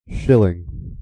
Ääntäminen
IPA : /ˈʃɪlɪŋ/